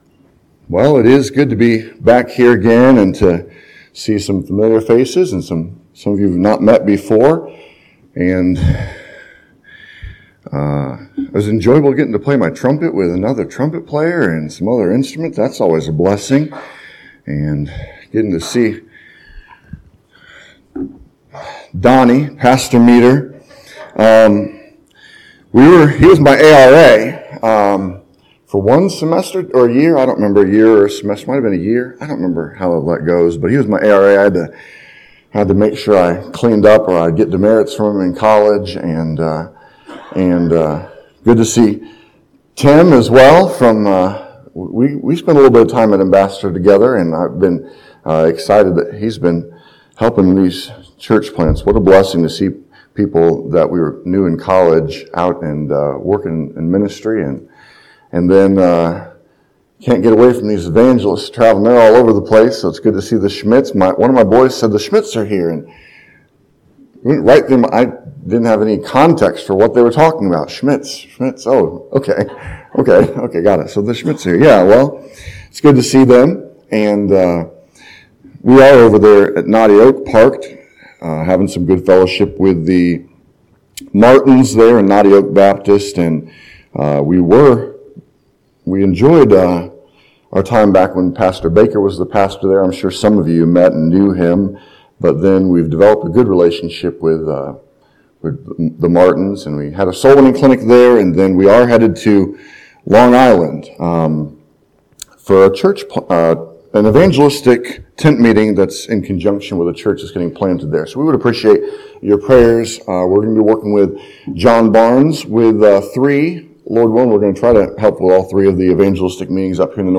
This sermon explains the full context of Psalm 126 and the need for the church to not remain dry eyed in a hell bound world.